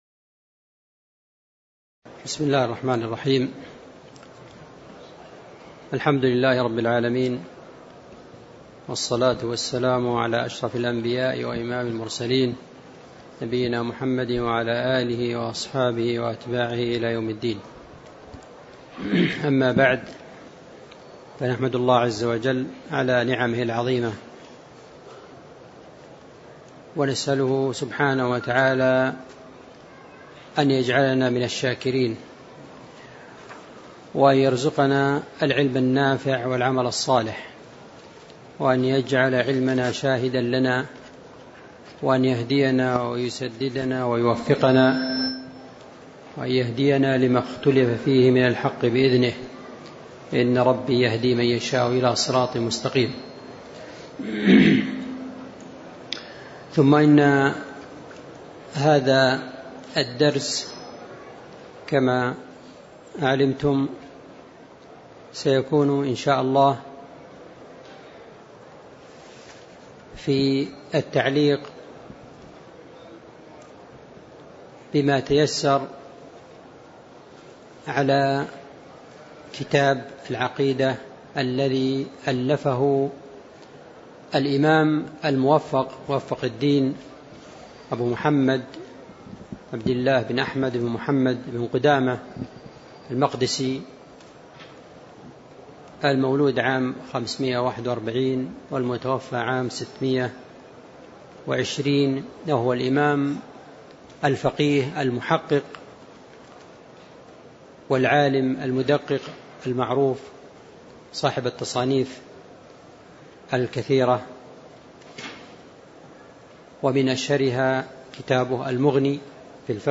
تاريخ النشر ٢٤ شوال ١٤٣٧ المكان: المسجد النبوي الشيخ